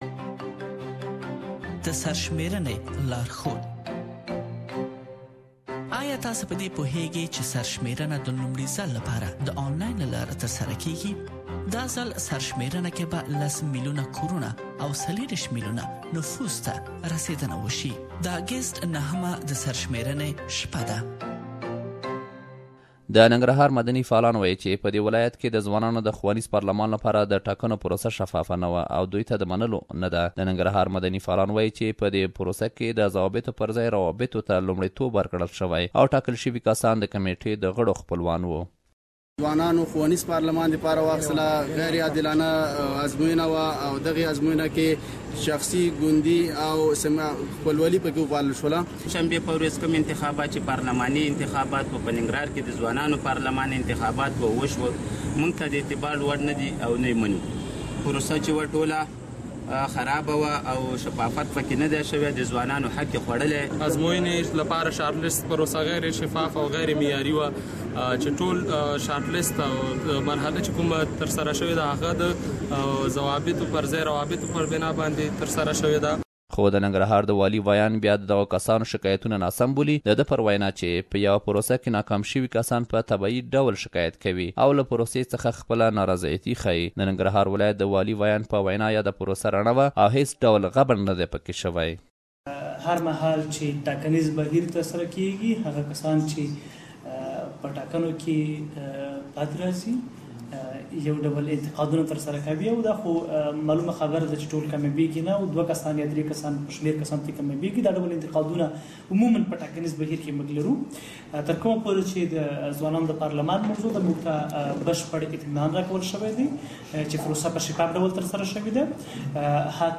In Nangrahar province of Afghanistan young residents took part in a youth educational parliaments election but the result was not satisfactory to some of civil society members. We have gathered some voices of the both sides of the issue and you can listen to the full report here.